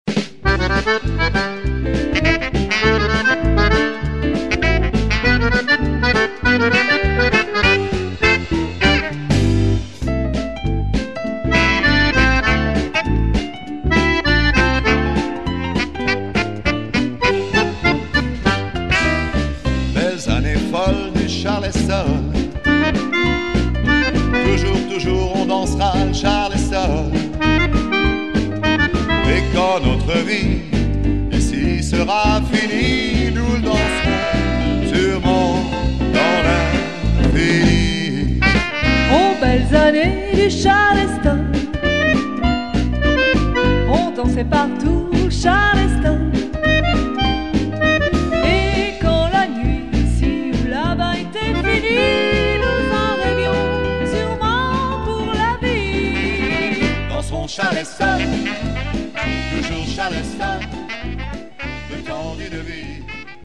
Charleston chanté